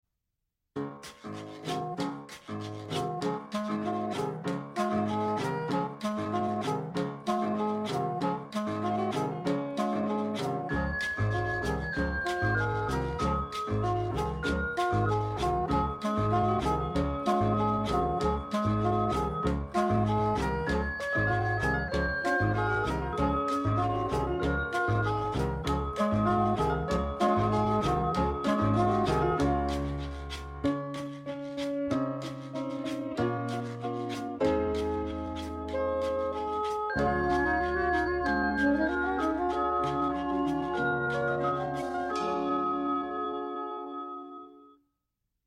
Low Pitched Version